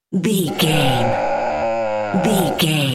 Dinosaur baby growl little creature
Sound Effects
scary
angry